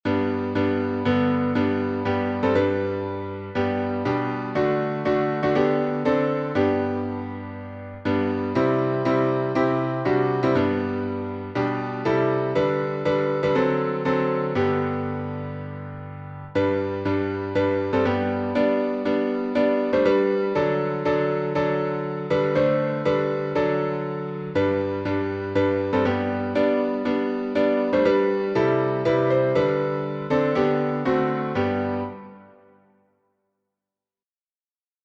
My Savior's Love — G major.